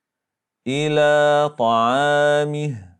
receteras med sukun när man stannar upp på det